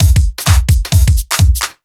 OTG_Kit 3_HeavySwing_130-D.wav